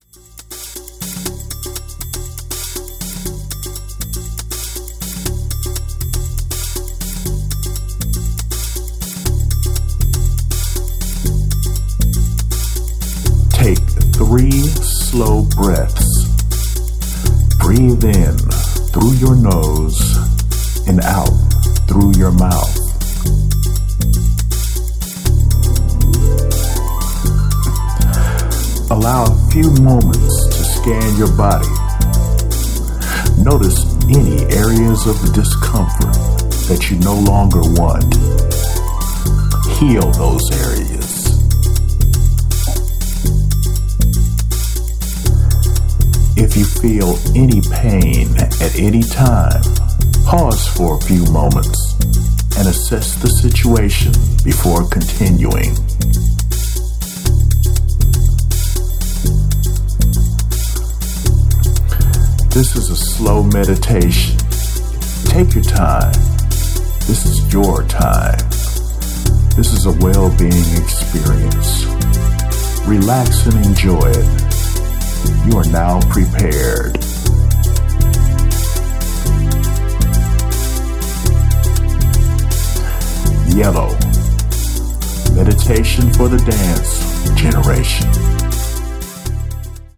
リラクシンマッサージ系心地良さ横溢チューン